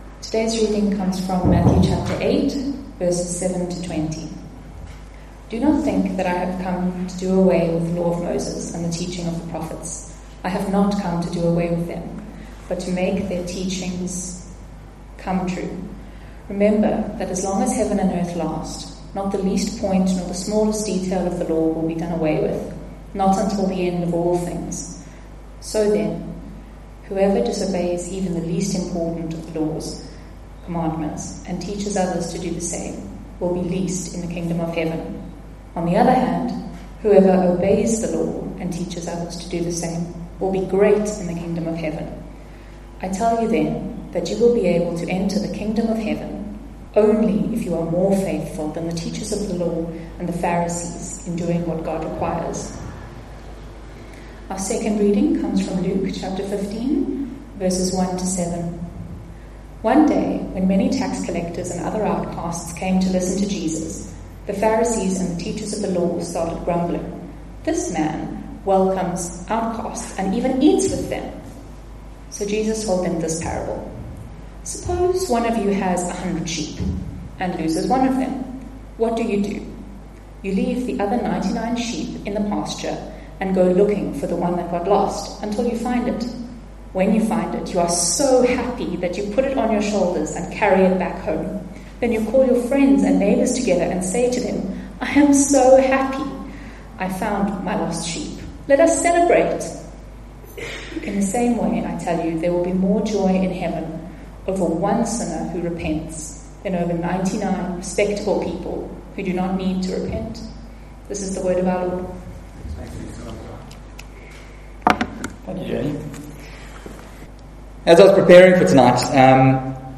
Trinity Methodist Church Sermons